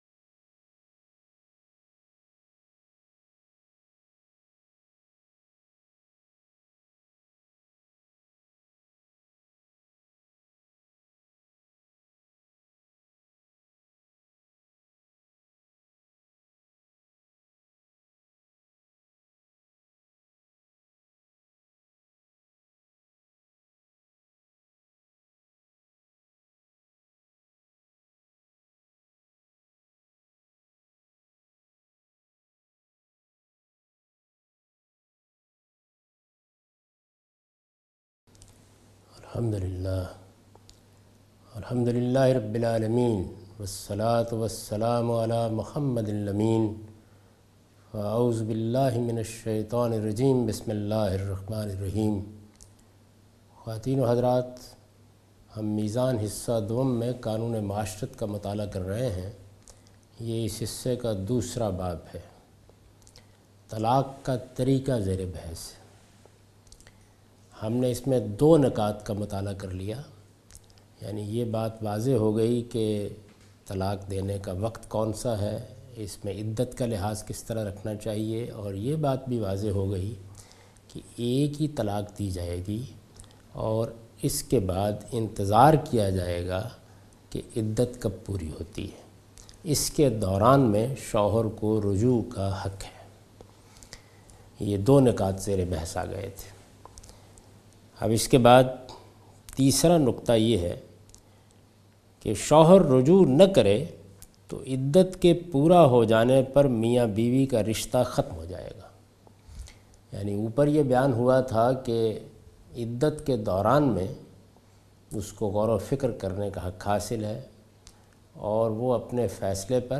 A comprehensive course on Islam, wherein Javed Ahmad Ghamidi teaches his book ‘Meezan’.
In this lecture he teaches ruling of divorce and Idat in Islam.